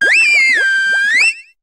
Cri d'Oratoria dans Pokémon HOME.